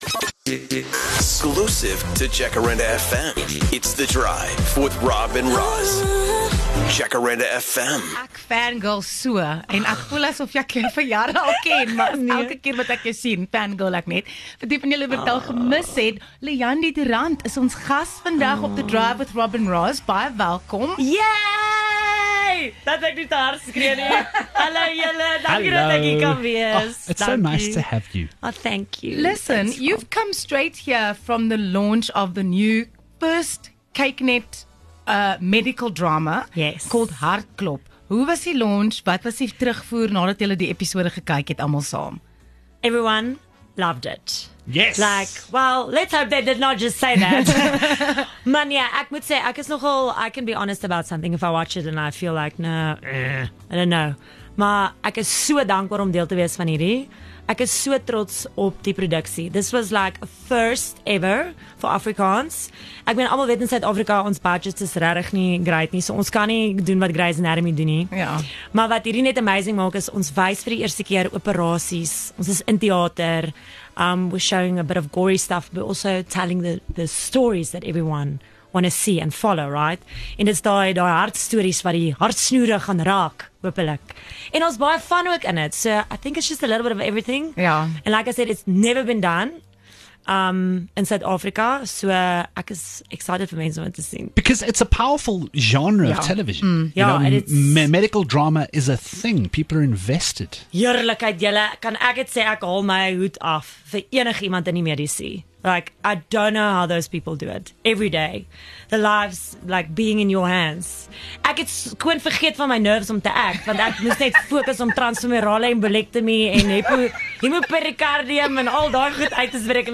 17 Jul EKSKLUSIEF: Leandie du Randt gesels oor nuwe kykNET-drama 'Hartklop'